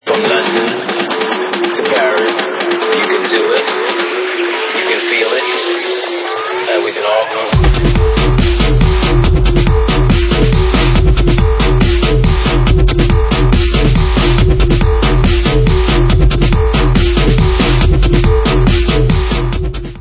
Dance/Techno